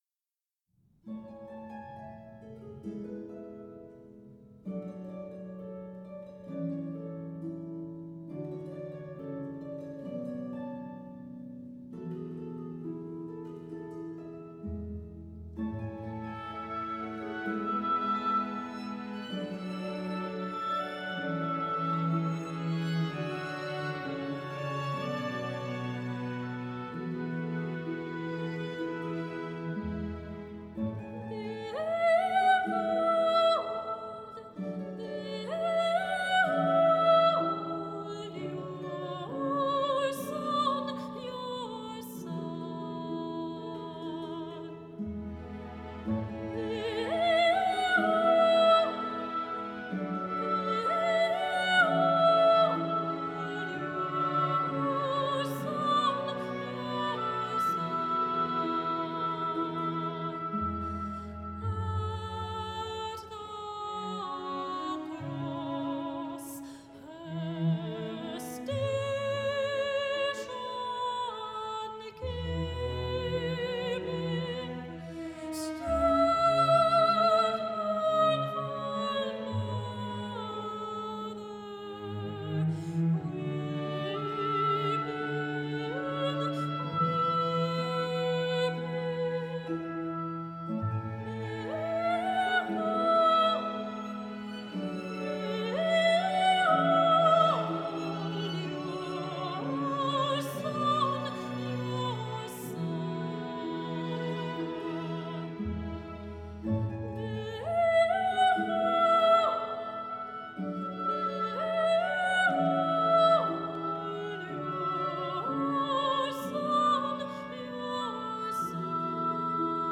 Genere: Choral.